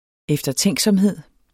Udtale [ εfdʌˈtεŋˀˌsʌmˀˌheðˀ ]